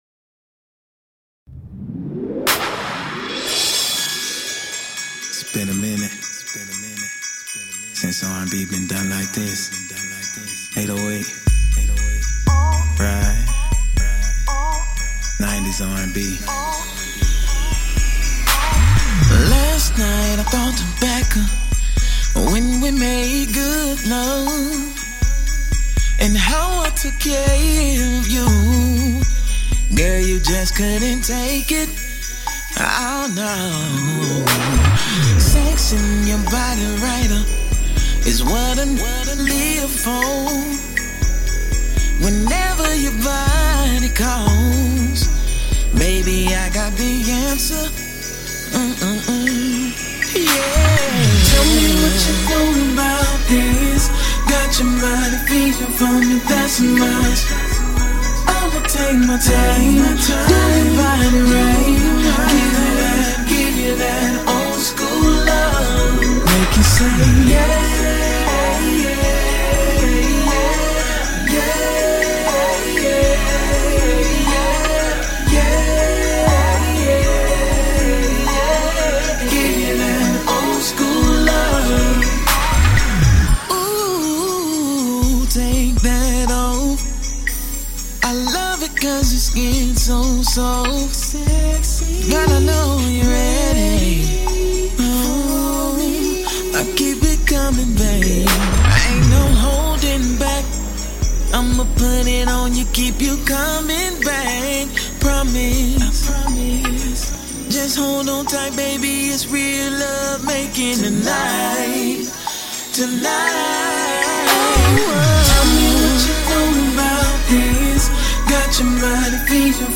The Onyoradiodial Show is a live talk show based on topics ranging from new niche's in the industry to open discussions.